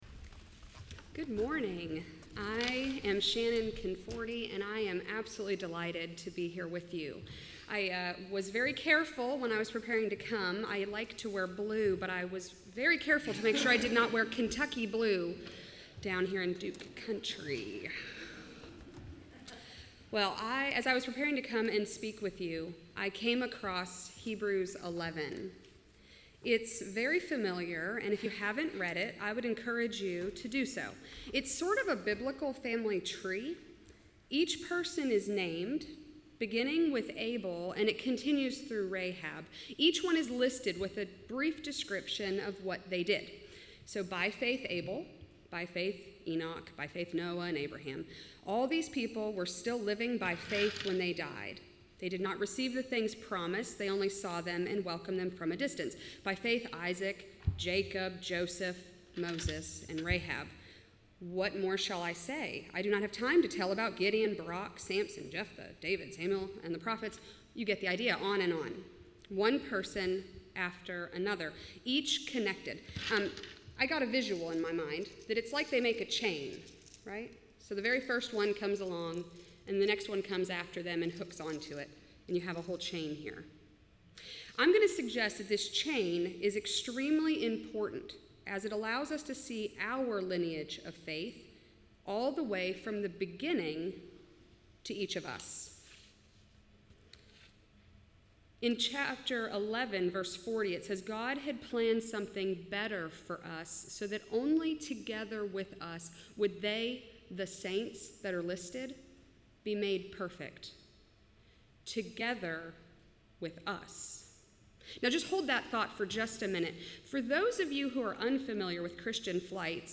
John 20:11-18 Service Type: Traditional Service Bible Text